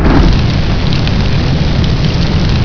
pyro_flameon.wav